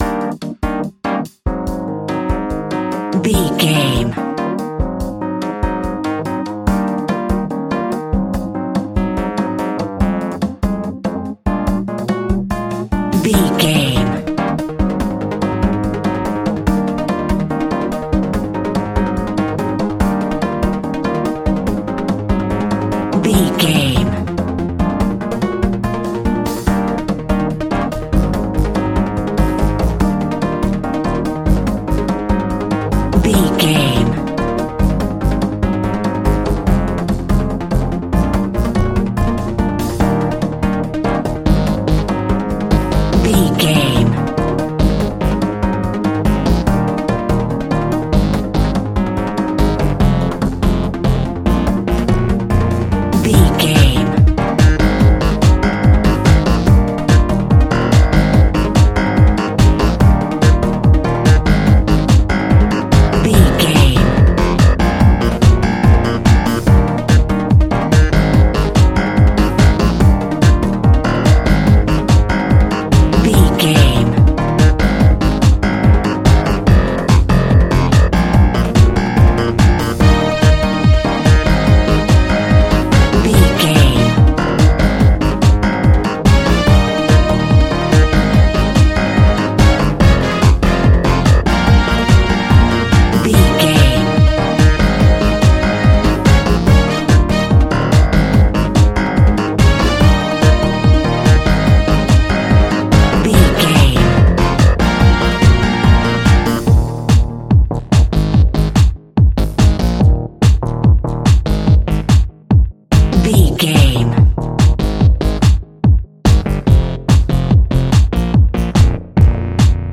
Uplifting
Ionian/Major
E♭
flamenco tracks
percussion
brass
saxophone
trumpet